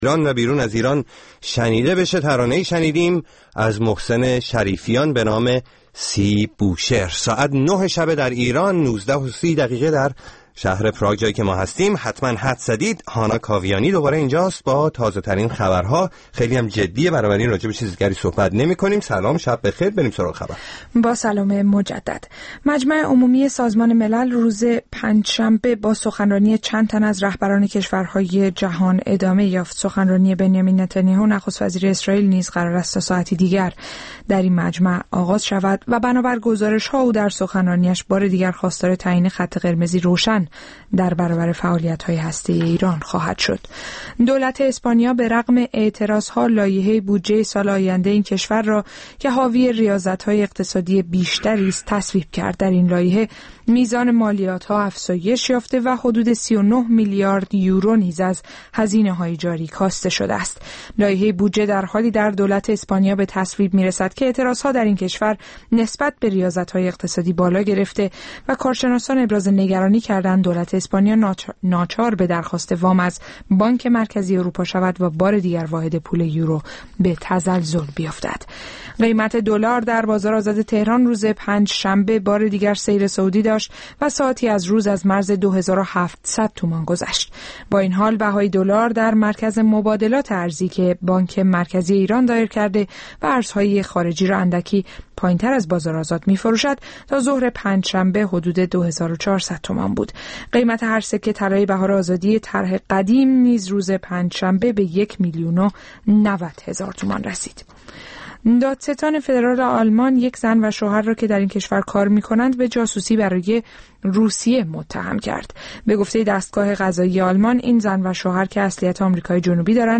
پنجشنبه‌ها از ساعت هشت شب به مدت دو ساعت با برنامه زنده موسیقی رادیو فردا همرا ه باشید.